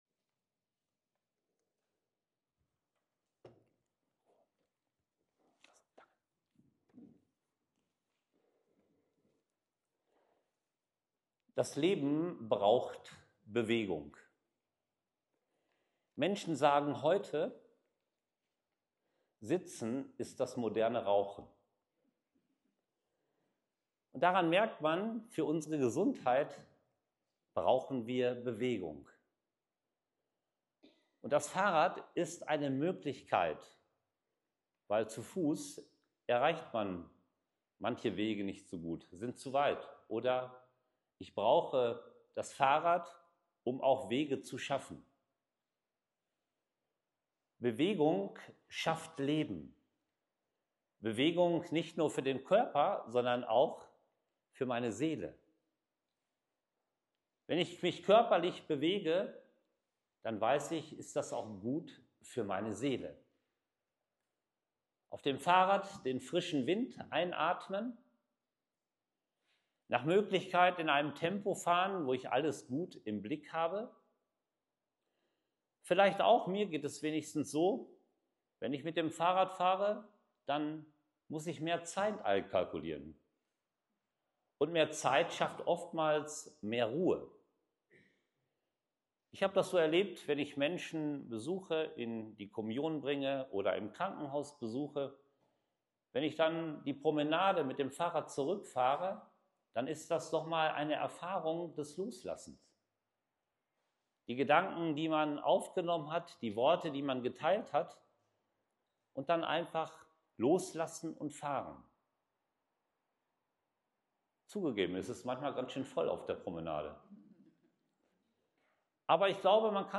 Fahrradpredigt
Audio-Fahrradpredigt.mp3